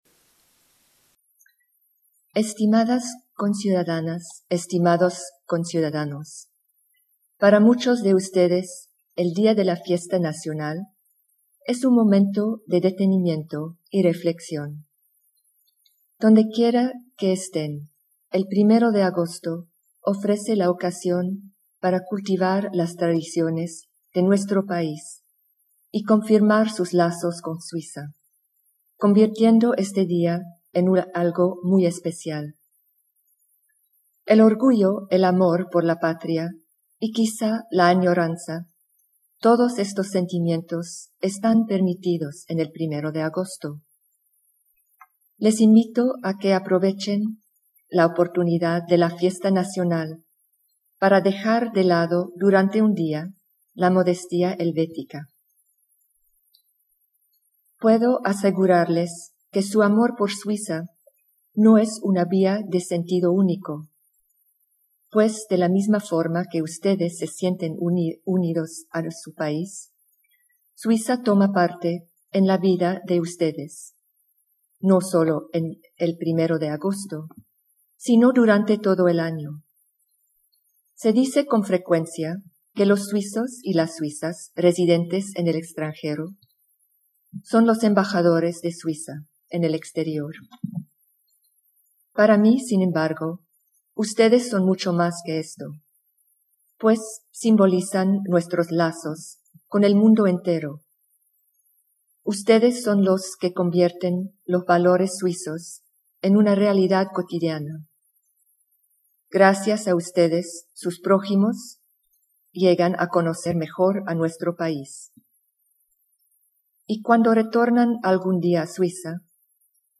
Mensagem da Senhora Presidente da Confederação Suíça Micheline Calmy-Rey aos suíços do estrangeiro por ocasião do Dia Nacional Suíço, 1° de agosto de 2011.